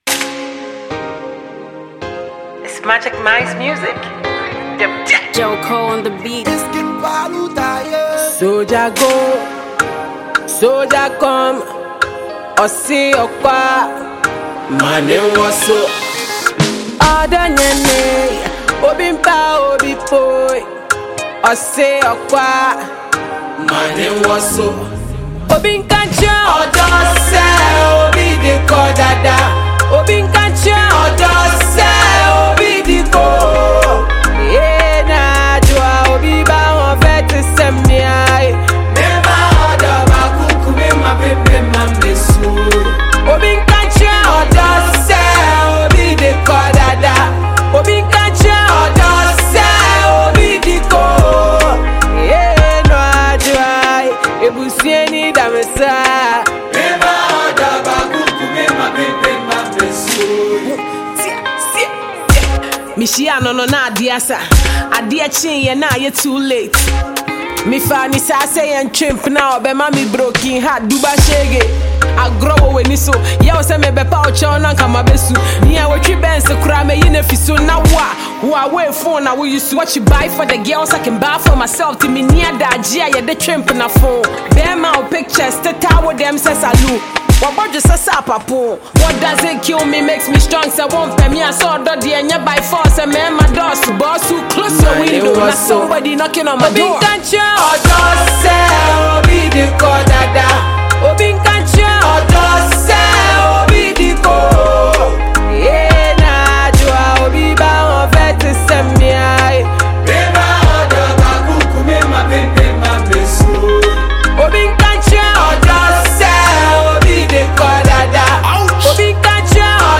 Ghanaian hardcore female rapper